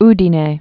(dē-nā)